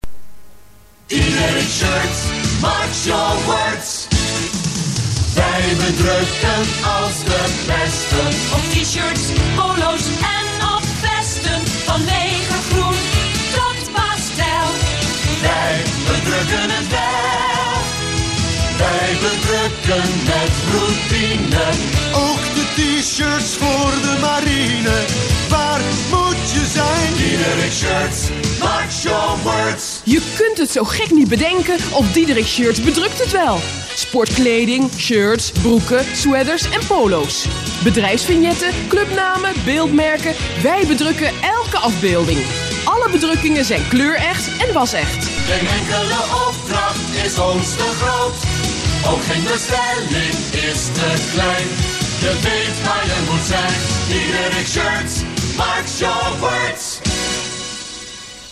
Radiocommercial
diederikshirtscommercial.mp3